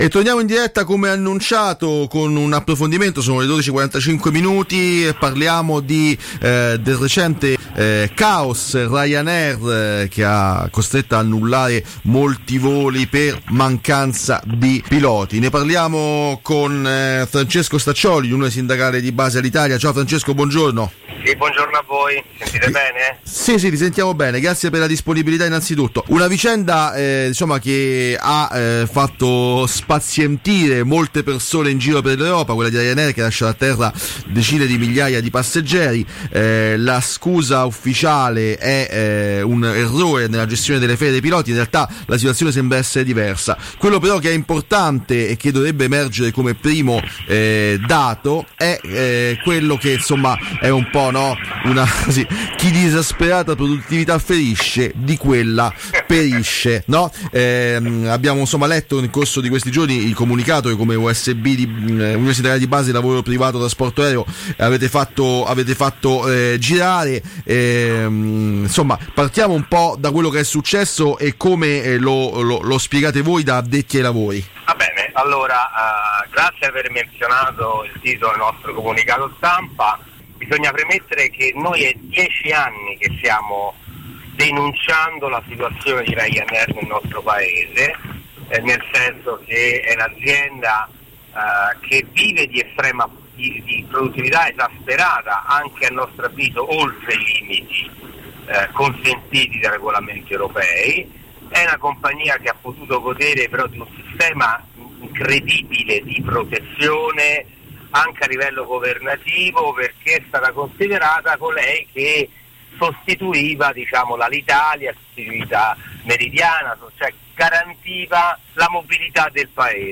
Caos Ryanair – Intervista